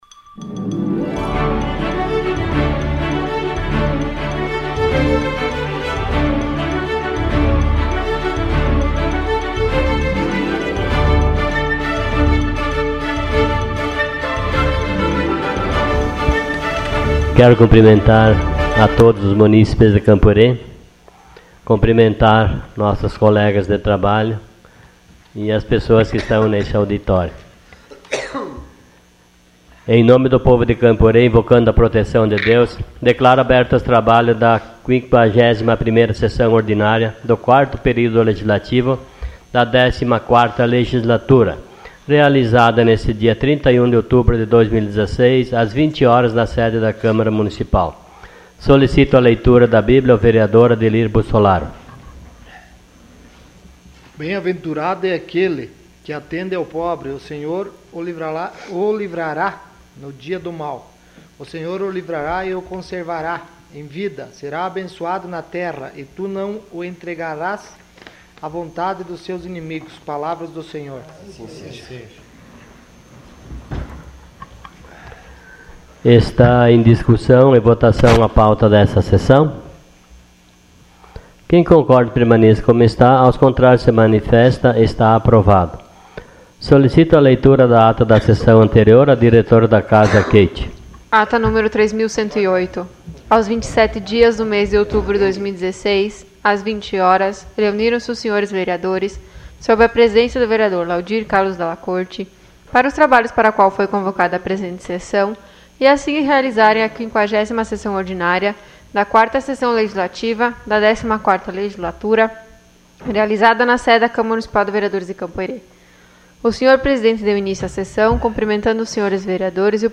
Sessão Ordinária dia 31 de outubro de 2016.